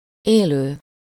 Ääntäminen
Synonyymit vif Ääntäminen France Tuntematon aksentti: IPA: /vi.vɑ̃/ Haettu sana löytyi näillä lähdekielillä: ranska Käännös Ääninäyte 1. eleven 2. élő 3. életben lévő Suku: m .